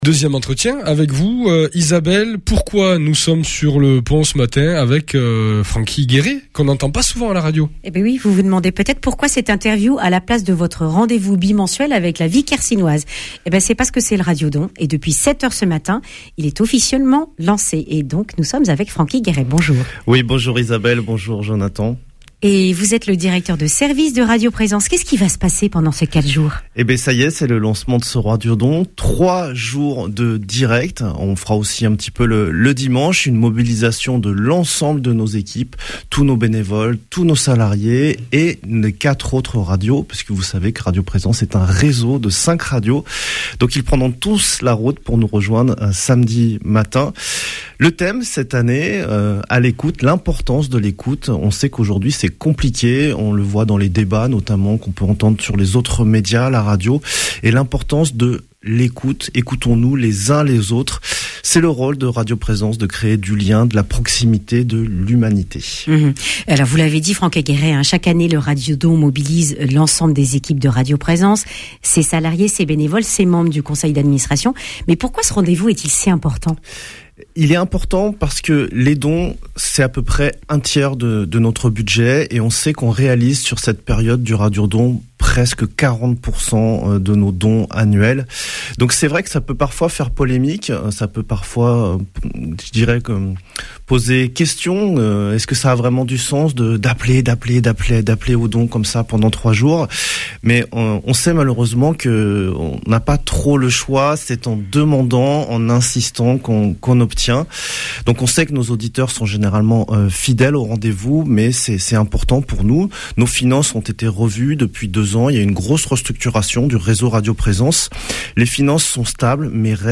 jeudi 27 novembre 2025 Le grand entretien Durée 10 min